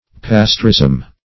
Search Result for " pasteurism" : The Collaborative International Dictionary of English v.0.48: Pasteurism \Pas*teur"ism\, n. [Fr. Pasteur, a French scientist.] 1.